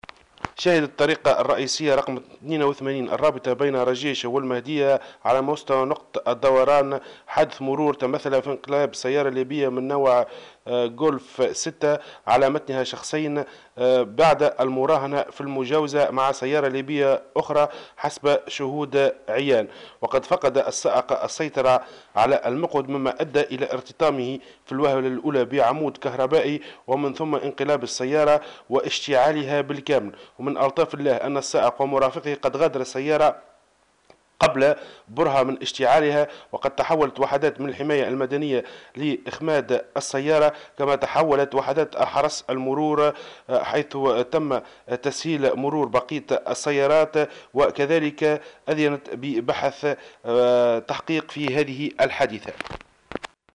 مراسلنا في المهدية تحميل المشاركة علي مقالات أخرى وطنية 17/04/2024 رئيس الجمهورية يستقبل رئيسة الوزراء الإيطالية دولية 17/04/2024 خلال 75 عامًا..